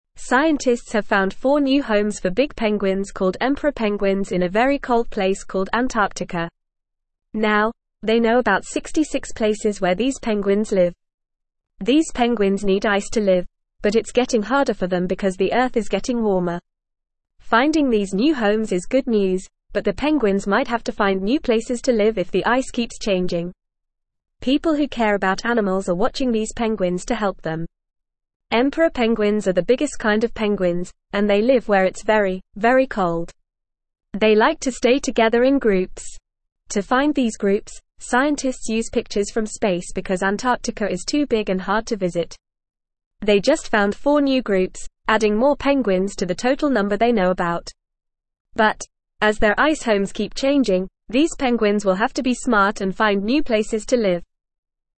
Fast
English-Newsroom-Beginner-FAST-Reading-New-Homes-Found-for-Big-Penguins-in-Antarctica.mp3